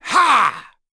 Zafir-Vox_Attack3.wav